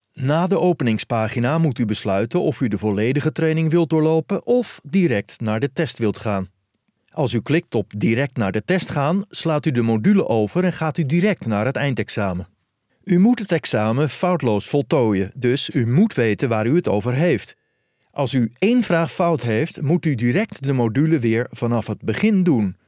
E-learning
I have a friendly, knowledgeable, dynamic and/or emotional voice, but I can strike a lot of other tones as well.
- Soundproof home studio
Baritone